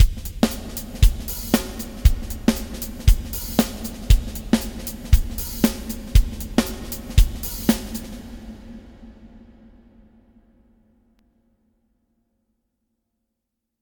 Gerade bei den Halleffekten ist die Auswahl groß, neben der ganzen Palette von kleinen Raumsimulationen mit dominanten Erstreflexionen bis hin zu riesigen Hallräumen sind auch Platten-, Feder- und Bandhall integriert.
Large Hall
alto_zephyr_zmx124fx_usb_testbericht_01_fx_large_hall.mp3